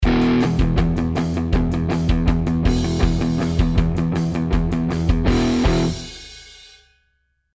Hard Rock Speed Guitar Lick
This one uses hammer-ons and pull-offs on sixteenth notes and triplets at a torrid 160bpm pace!
Audio at 160bpm, 80bpm, and a backing track at full speed are included below.
LRT-025-Hard-Rock-Speed-Lick-Backing-Track.mp3